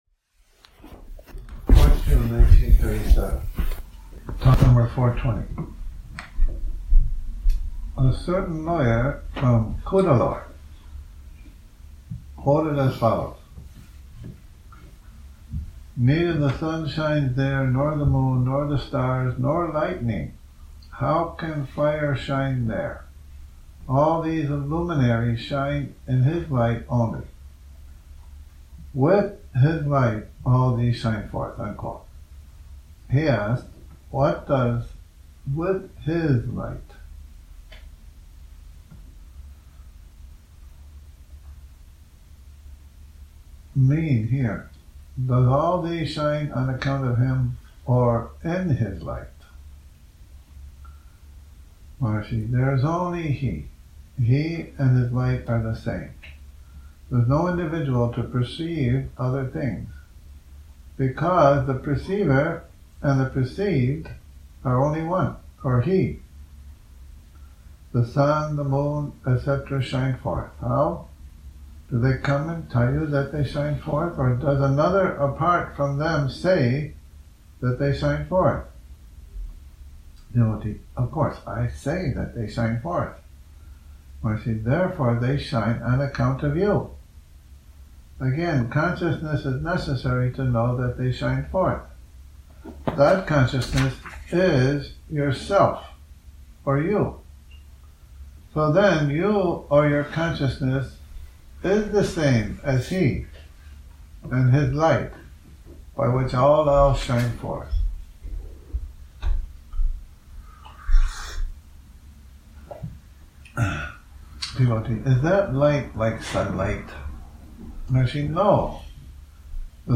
Morning Reading, 03 Oct 2019
a reading from 'Talks', #420 - #432 Morning Reading, 03 Oct 2019 recording begins near the end of the 4th page after the beginning of talk #399